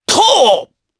Oddy-Vox_Attack2_jp.wav